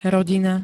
Zvukové nahrávky niektorých slov